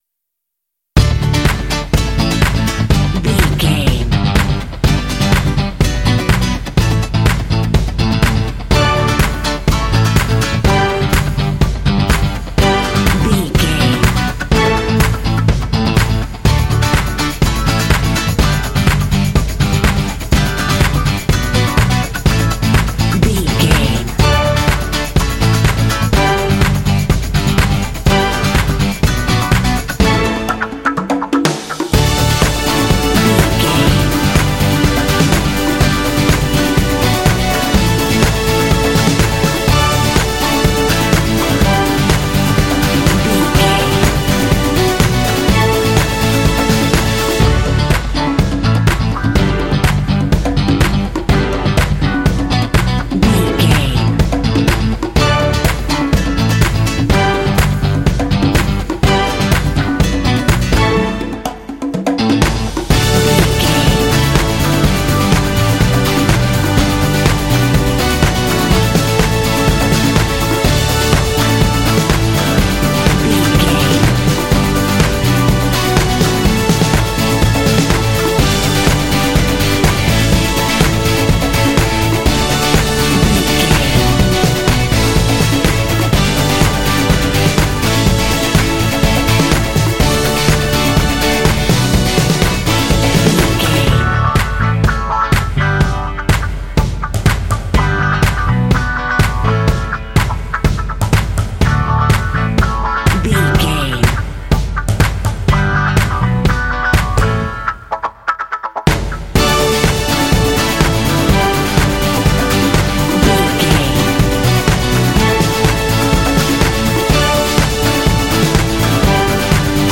Uplifting
Ionian/Major
D
cheerful/happy
joyful
funky
drums
strings
acoustic guitar
electric guitar
bass guitar
percussion
synthesiser
alternative rock